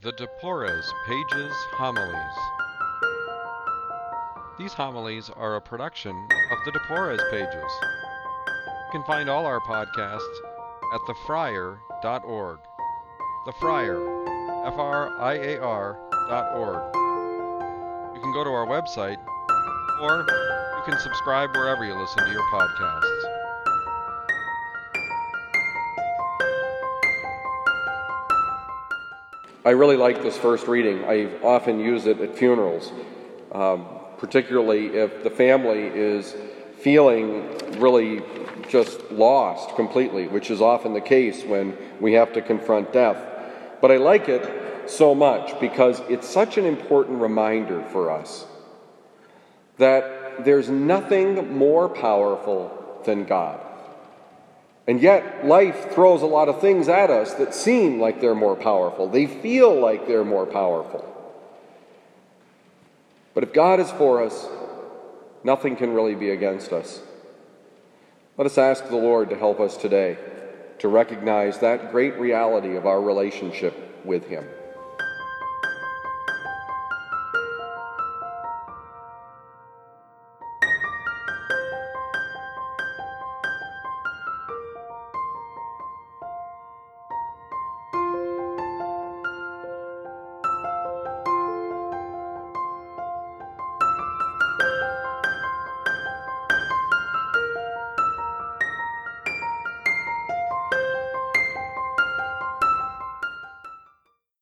Homily given at Christian Brothers College High School, Town and Country, Missouri, October 31, 2019.